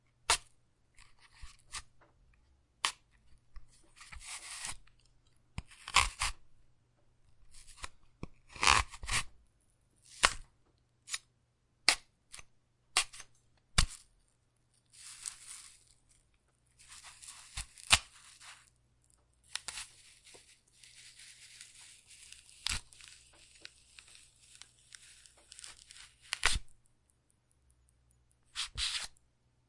厨房的声音 " 刀在盘子里 1
描述：一堆学生时代从我的厨房录下的声音，录音质量相当好。
标签： 厨房 废料 食品
声道立体声